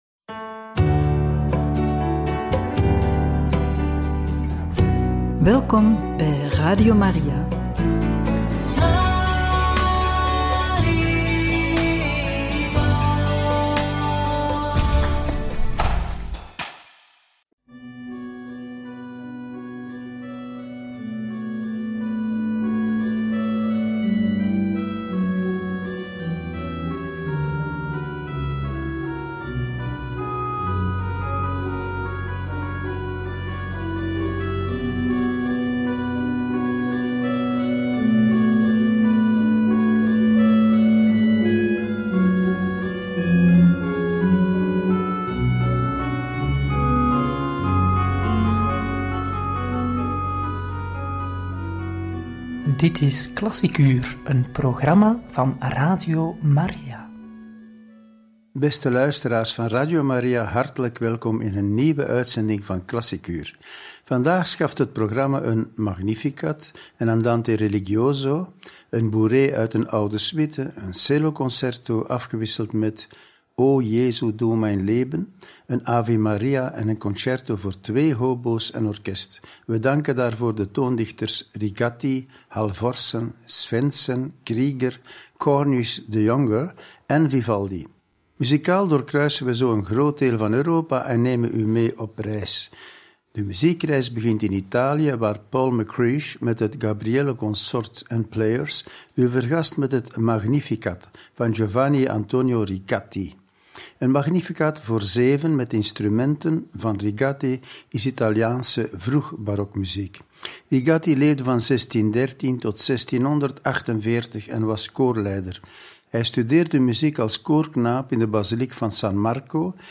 Een Magnificat, een Andante Religioso, een Bourrée uit een oude suite, een Celloconcerto en meer!